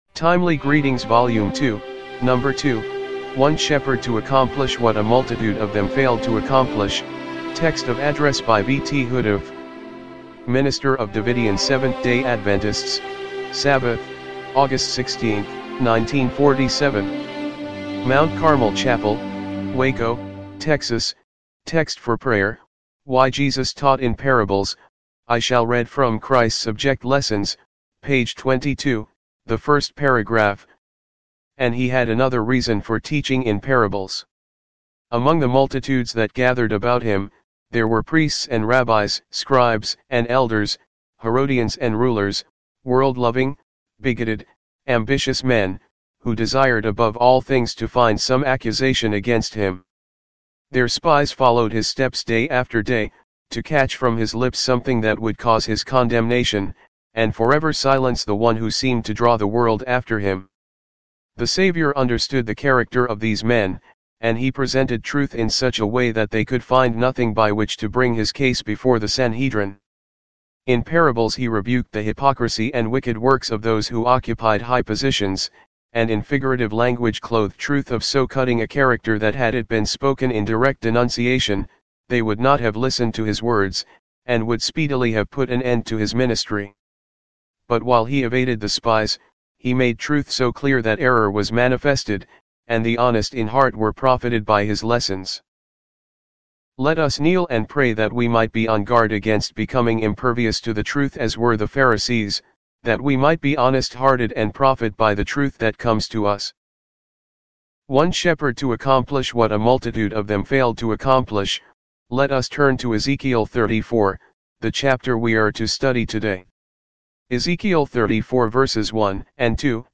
TEXT OF ADDRESS
1947 MT. CARMEL CHAPEL WACO, TEXAS